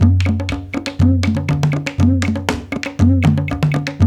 120 -CONG00L.wav